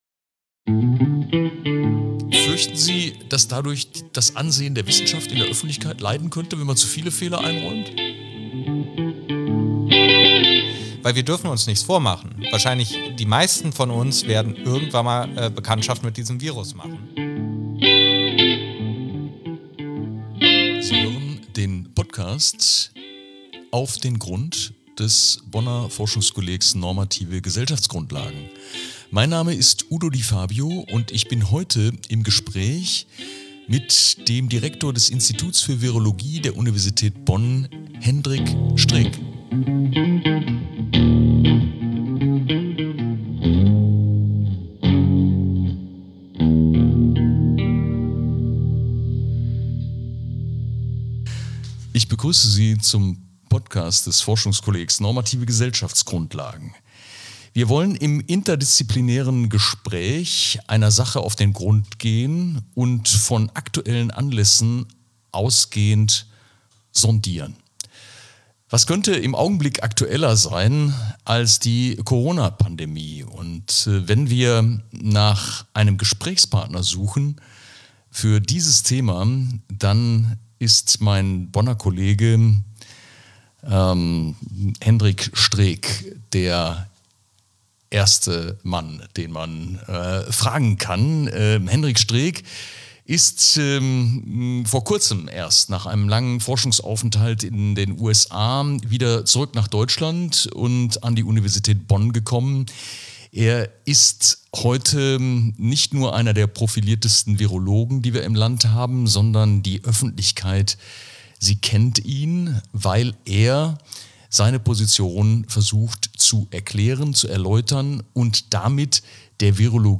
Im Gespräch mit Udo Di Fabio geht es nicht nur um die aktuelle Lage der Pandemieforschung und -bekämpfung, sondern auch um die Rolle der Wissenschaft selbst. Die Forschung begegnet dabei nicht nur Schwierigkeiten in der Sache, sondern sieht sich auch Hürden bei der Kommunikation vorläufiger, oft unsicherer Ergebnisse ausgesetzt.